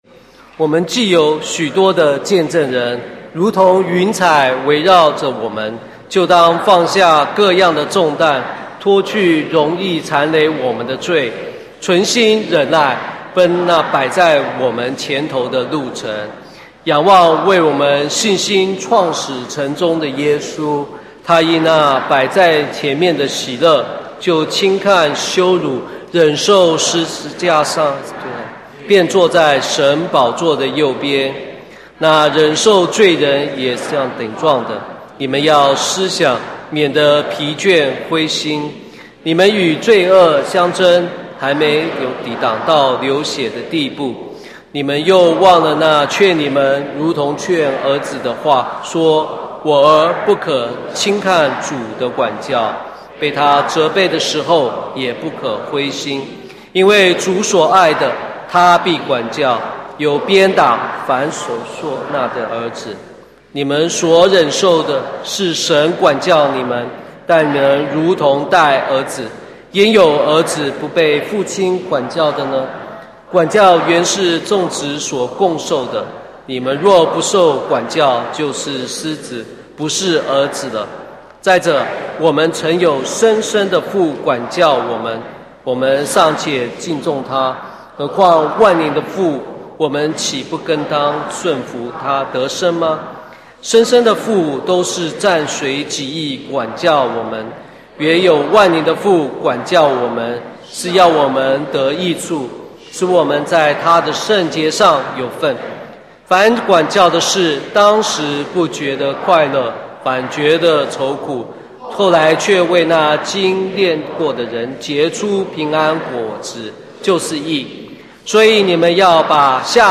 Sermon | CBCGB